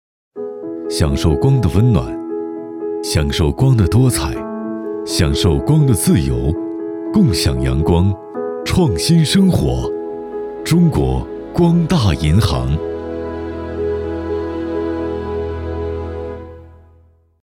专注高端配音，拒绝ai合成声音，高端真人配音认准传音配音
男42